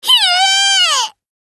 Play Hiei Shout - SoundBoardGuy
hiei-shout.mp3